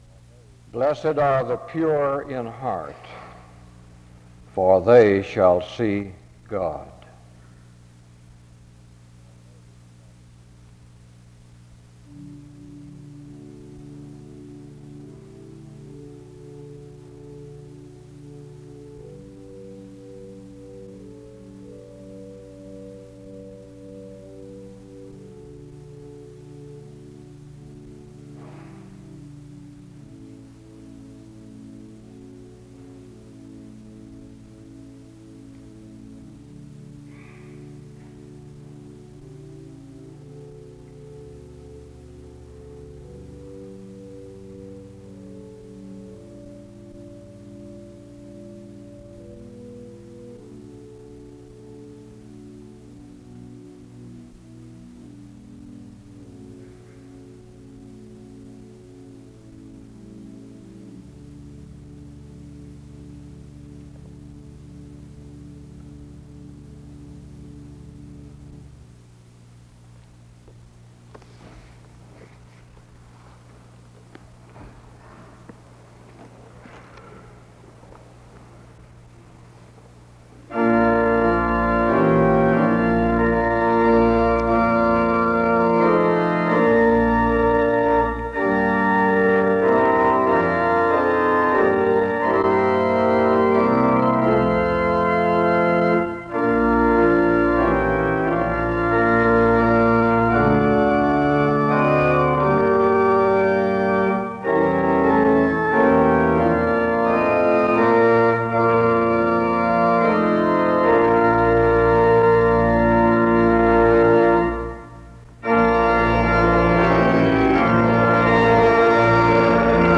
Music plays from 8:21-10:34.
He preaches on the divine mystery and beauty of spiritual courage in the face of adversity. The service closes with music from 24:06-25:29.